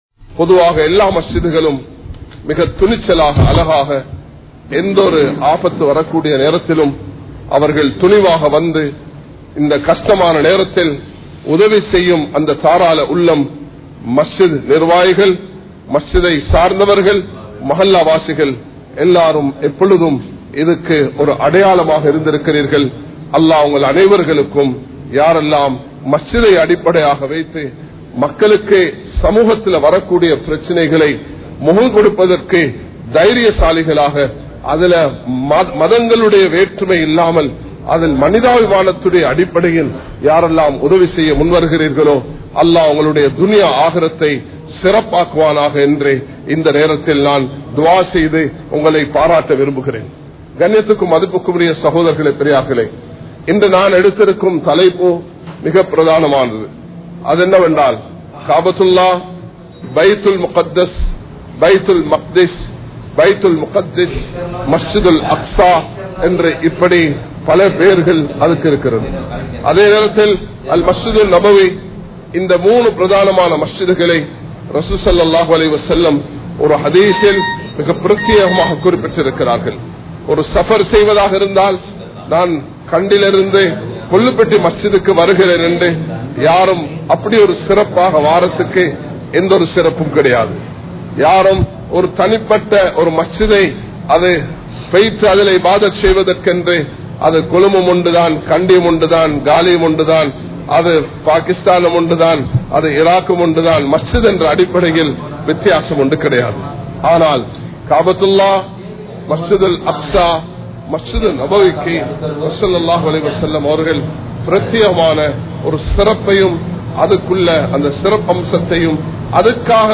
Hajj (ஹஜ்) | Audio Bayans | All Ceylon Muslim Youth Community | Addalaichenai
Kollupitty Jumua Masjith